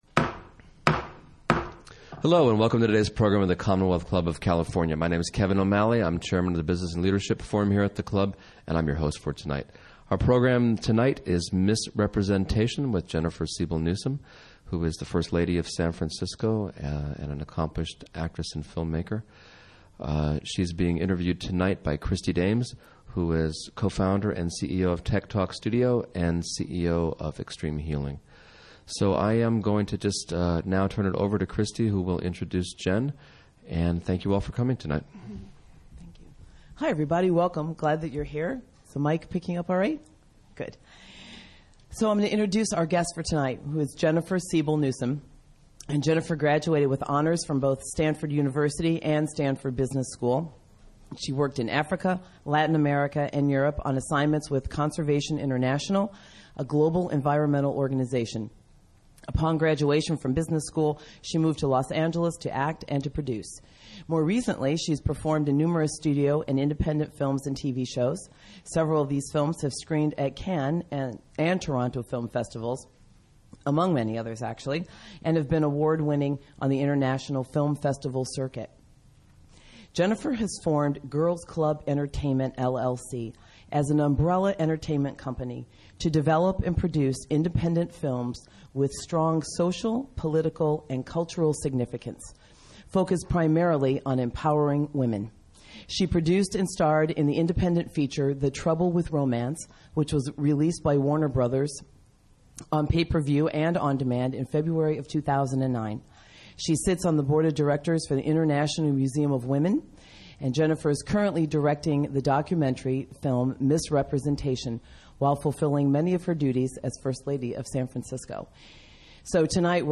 Don’t miss this rare opportunity to hear from the first lady of San Francisco.